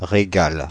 Ääntäminen
Ääntäminen Paris Tuntematon aksentti: IPA: /ʁe.ɡal/ Haettu sana löytyi näillä lähdekielillä: ranska Käännöksiä ei löytynyt valitulle kohdekielelle.